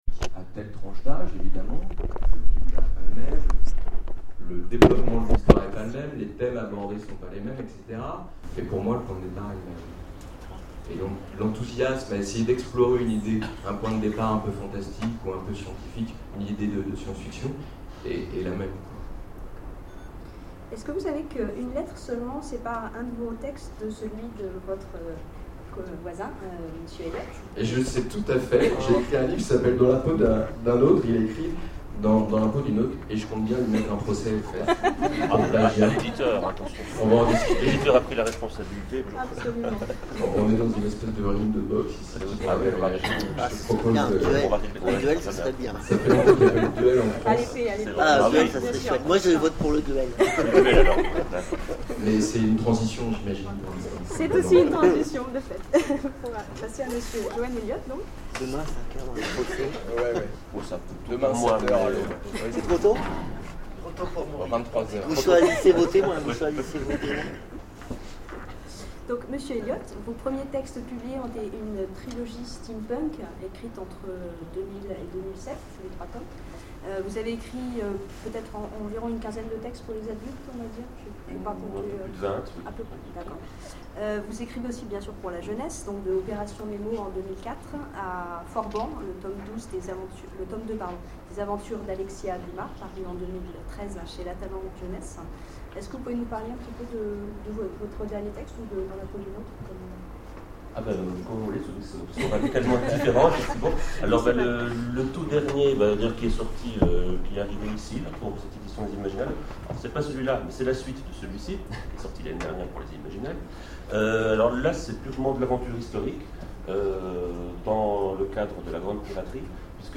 Imaginales 2013 : Conférence Ecrire pour de jeunes lecteurs...